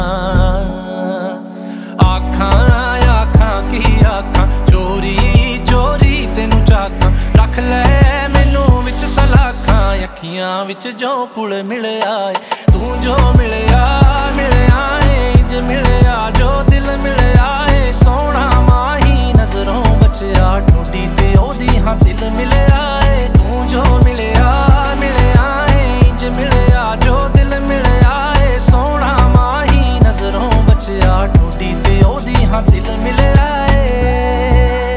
Жанр: Поп / Инди
# Indian Pop